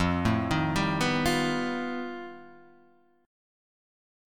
F9b5 Chord